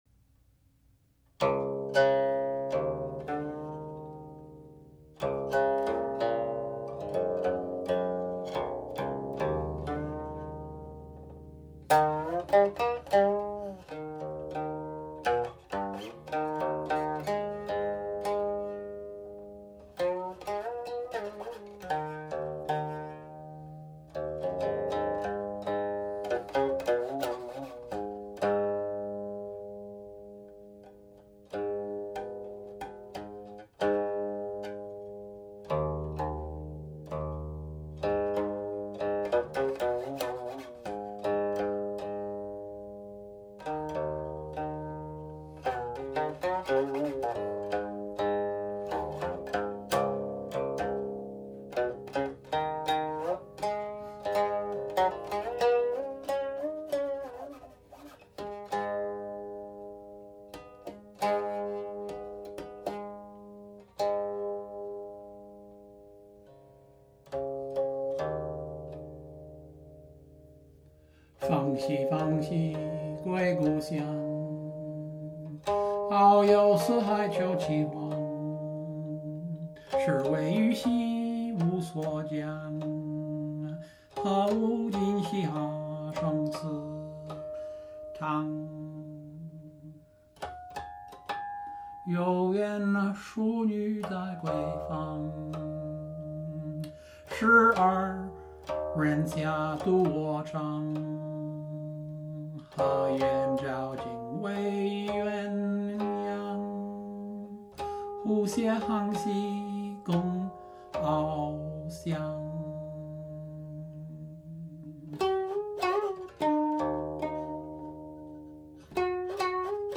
The present Feng Qiu Huang has 10 sections in all; the lyrics are in sections 3 and 8.
Ten sections, untitled; timings follow
01.06     3. (harmonics; lyrics)
04.04     8. (harmonics; lyrics)
06.02           harmonic coda
From standard tuning lower the third and sixth strings (details).